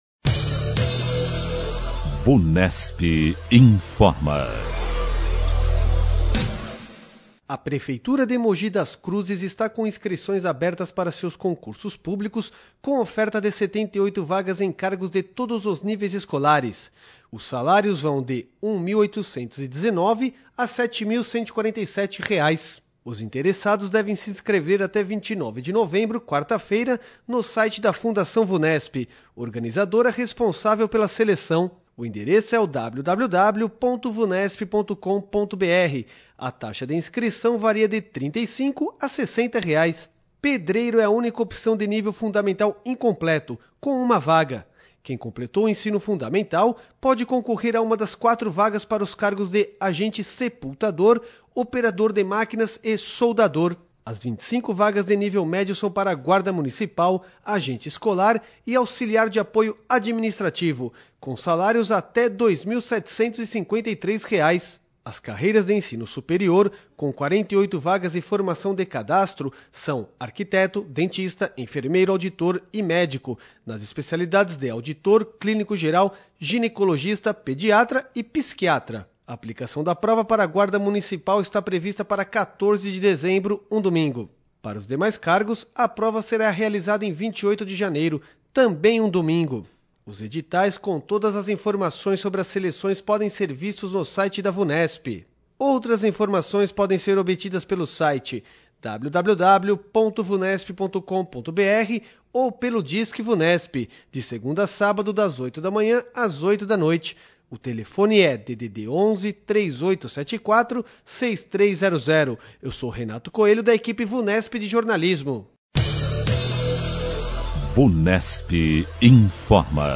A equipe de jornalistas da Vunesp apresenta as últimas informações sobre concursos, vestibulares e avaliações feitas pela instituição.